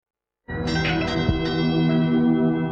Le stage rap ragga
En juillet 2003, l'association des Zetlaskars a aidé une dizaine de jeunes à concevoir des chansons de rap.